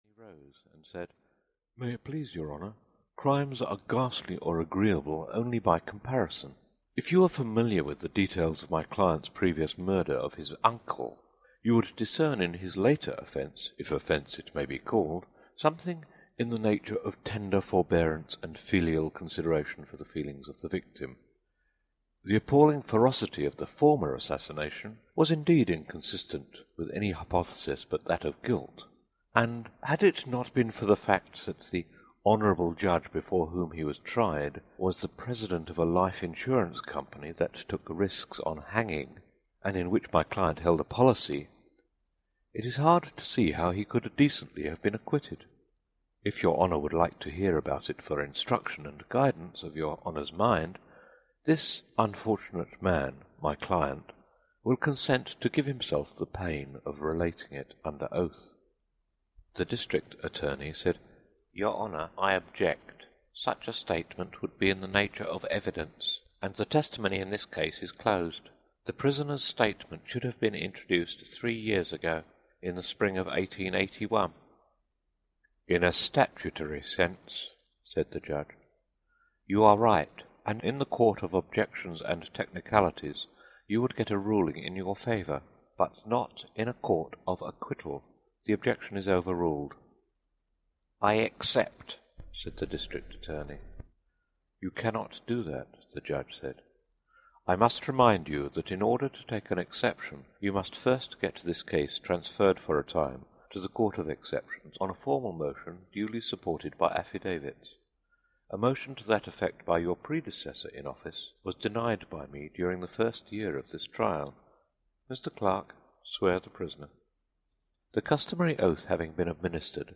The Parenticide Club (EN) audiokniha
Ukázka z knihy